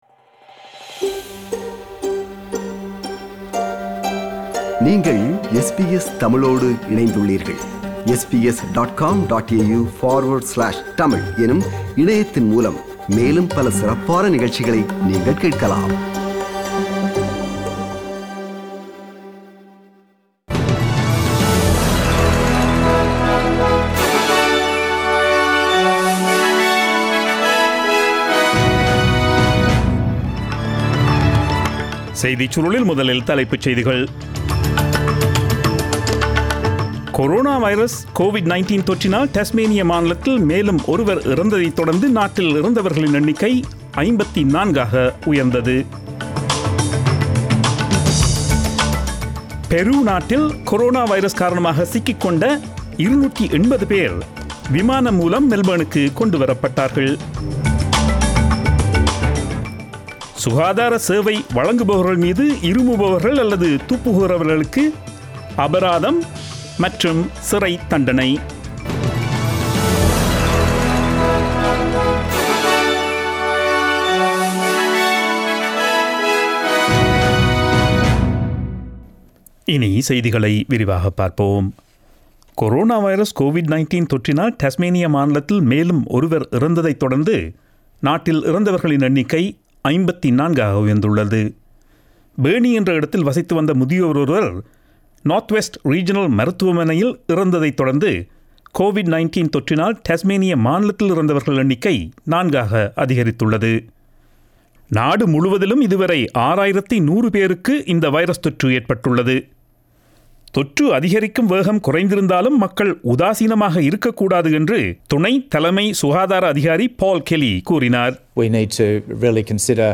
Australian news bulletin aired on Friday 10 April 2020 at 8pm.